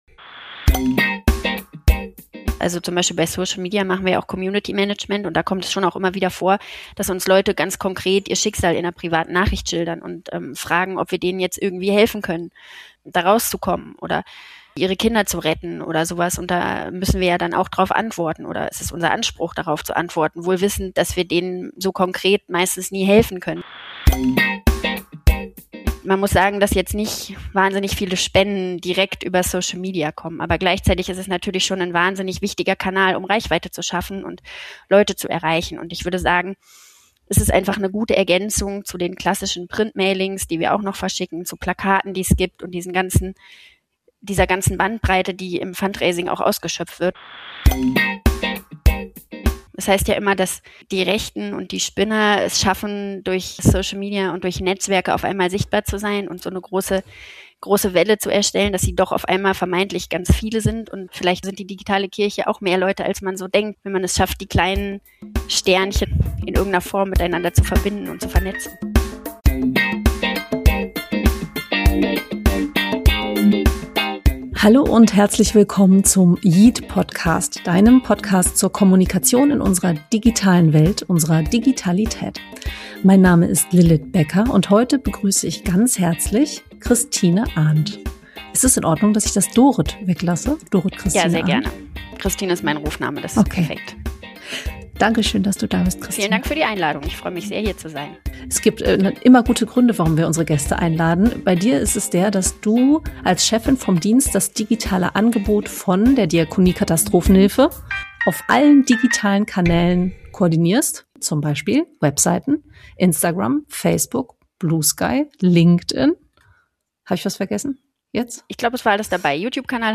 Social Media für Glaube und Kirche - das ist der yeet-Podcast: yeet-Redakteur* innen befragen Expert* innen und Influencer* innen und begeben sich auf die Suche nach den großen und kleinen Perspektiven auf die digitalen Kirchen-Räume und Welten in den Sozialen Medien.